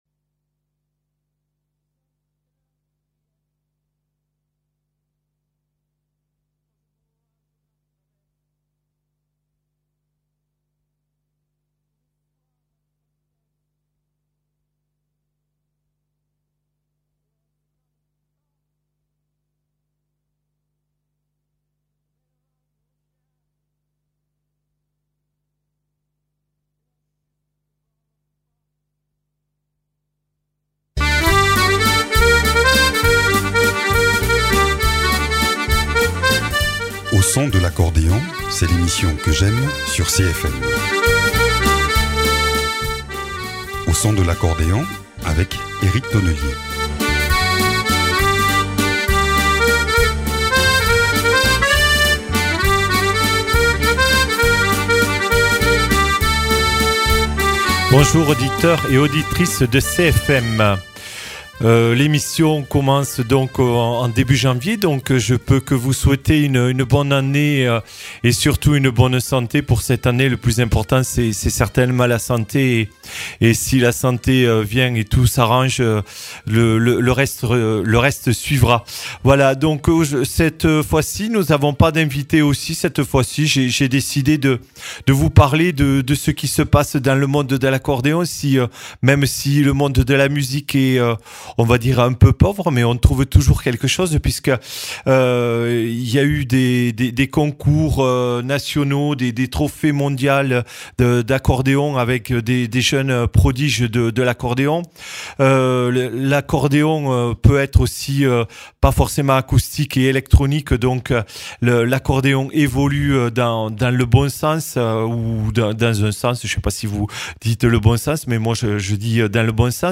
En ce début d’année, un tour du monde de quelques musiques d’accordéonistes. Aussi, malgré le peu d’actualité, quelques dates à noter dans vos agendas.